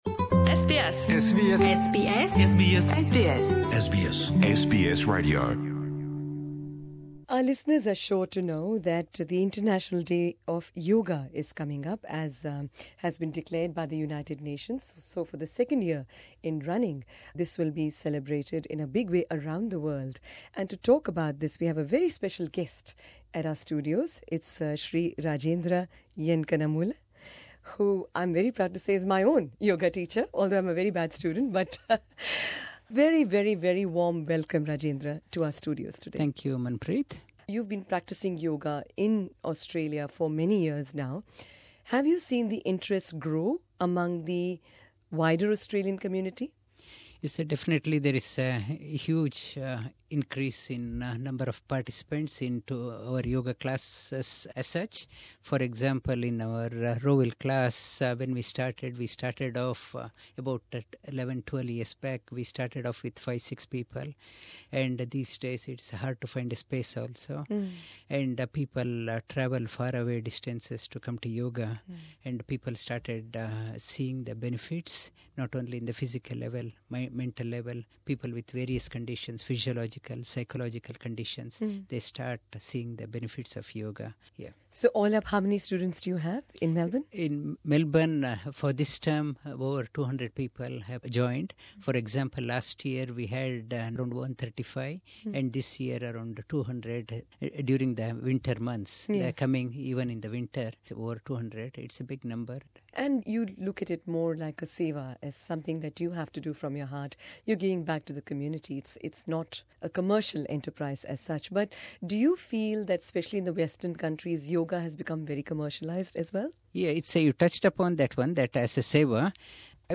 Speaking to SBS Punjabi at our Melbourne studios
In this interview